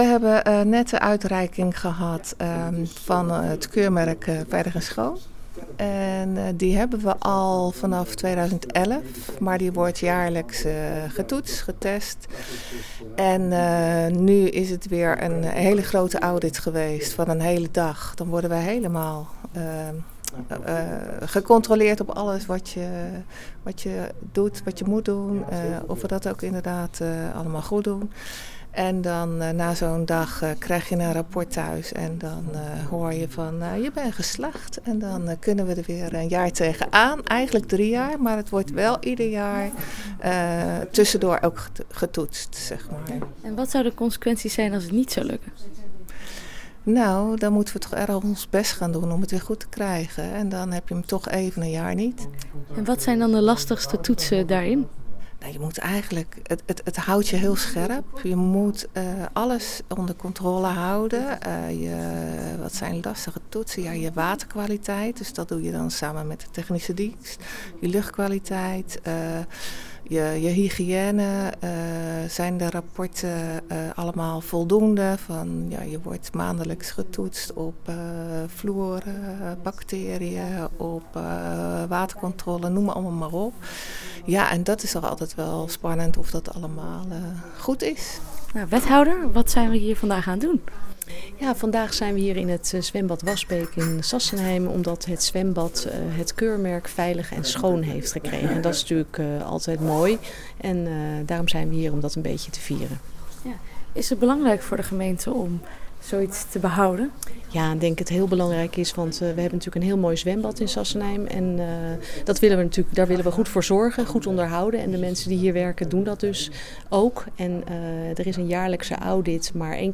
Audioreportage: